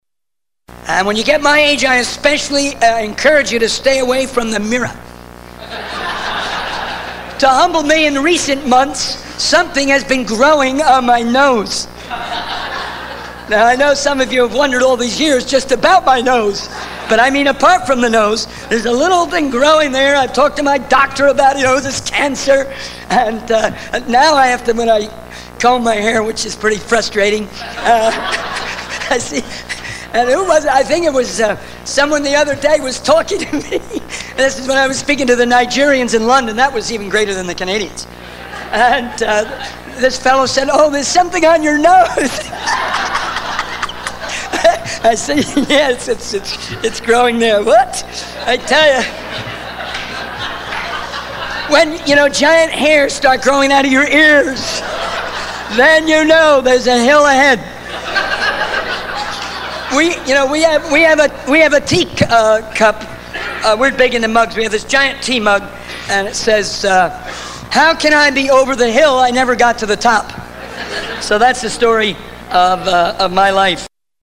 The sermon is a humorous reflection on the challenges and humbling effects of aging, encouraging listeners to stay humble and grateful for what they have.
The speaker seems to take a humorous and lighthearted approach to aging, using humor to cope with the challenges it brings.